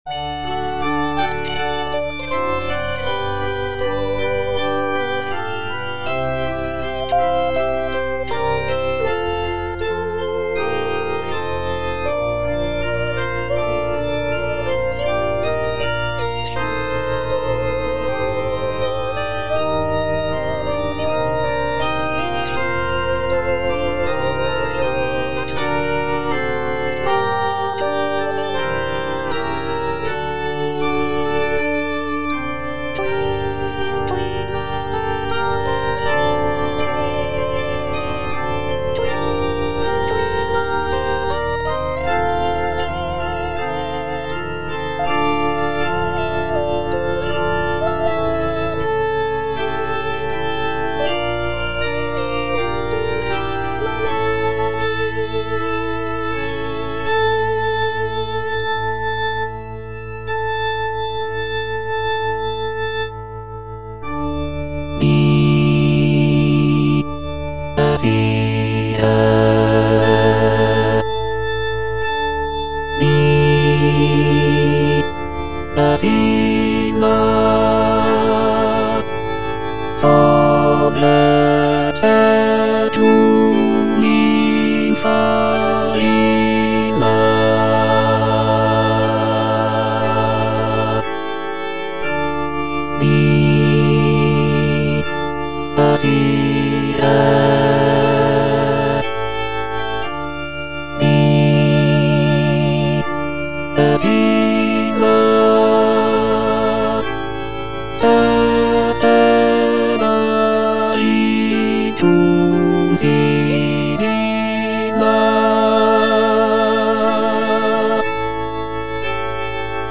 Bass (Emphasised voice and other voices) Ads stop
is a sacred choral work rooted in his Christian faith.